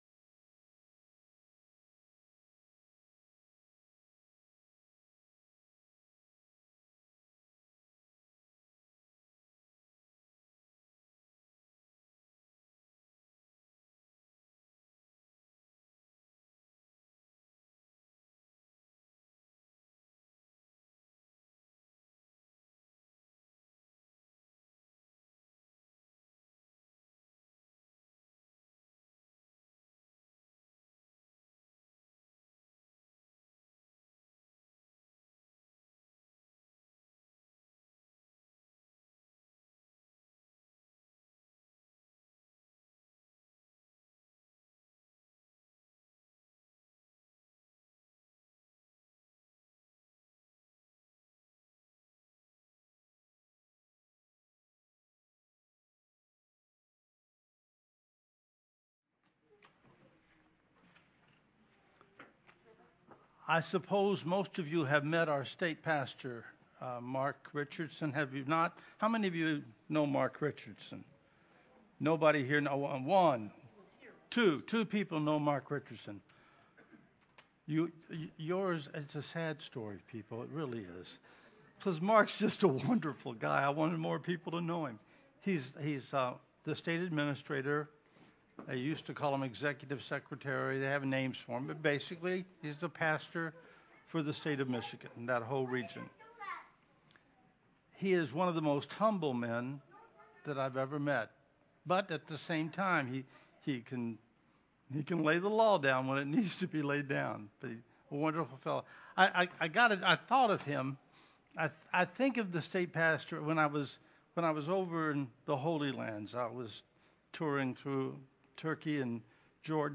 “When the Spirt come” Sermon